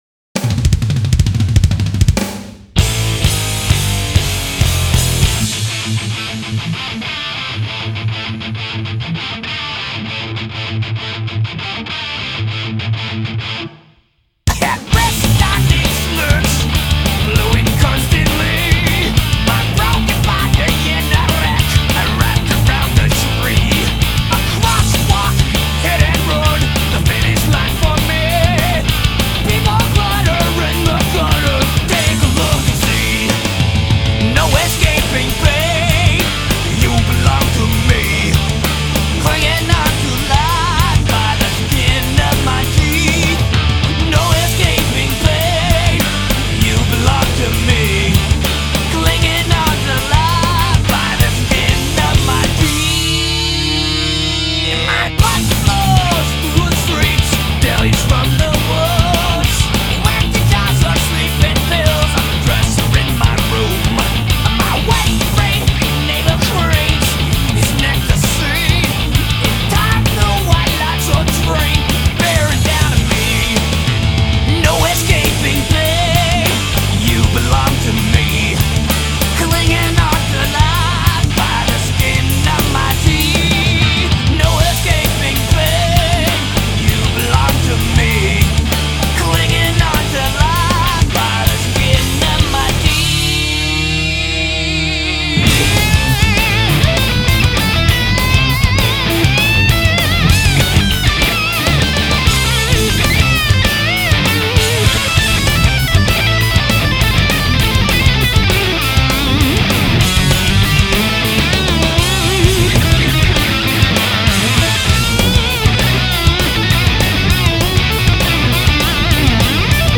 ترش متال ثرش متال